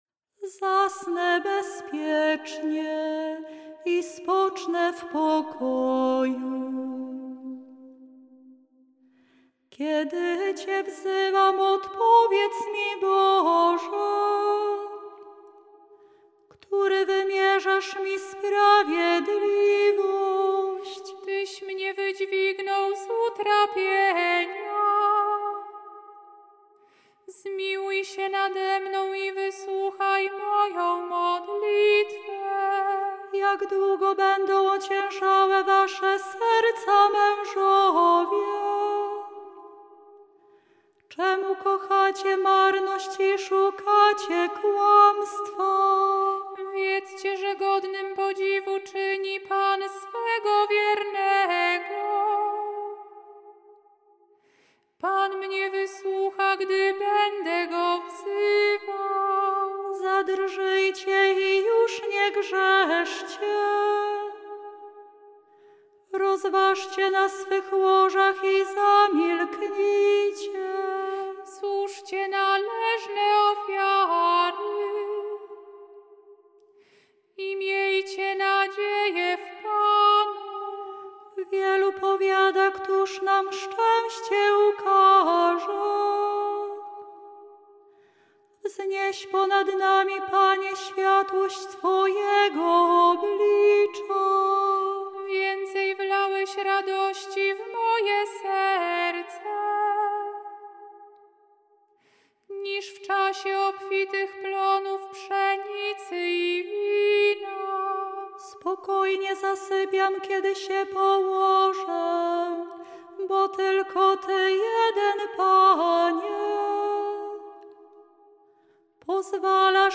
Dlatego do psalmów zastosowano tradycyjne melodie tonów gregoriańskich z ich różnymi, często mniej znanymi formułami kadencyjnymi (tzw. dyferencjami).
Dla pragnących przygotować się do animacji i godnego przeżycia tych wydarzeń liturgicznych przedstawiamy muzyczne opracowanie poszczególnych części wykonane przez nasze siostry